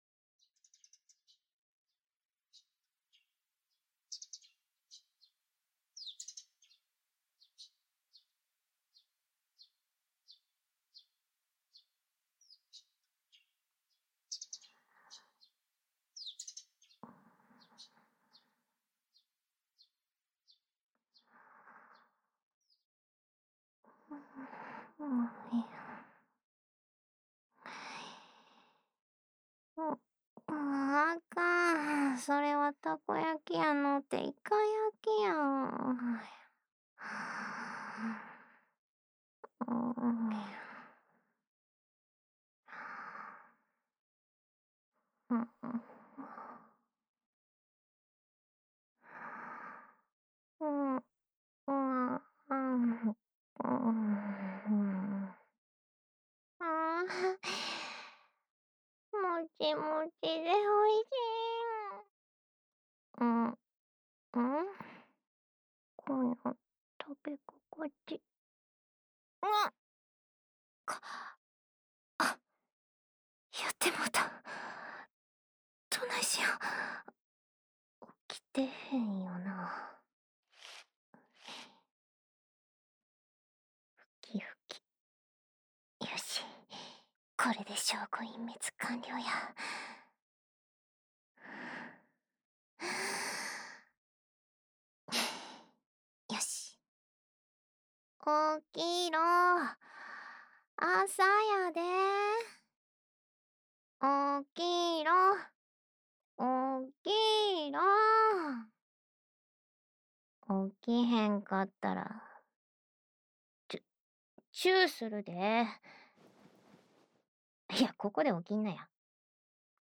日常/生活 治愈 关西腔 掏耳 环绕音 ASMR 低语